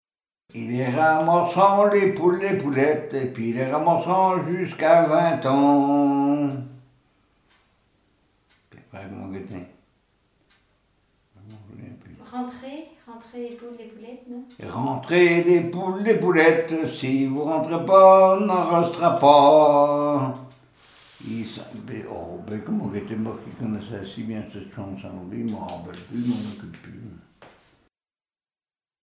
Patois local
Chants brefs - Conscription
gestuel : à marcher
Pièce musicale inédite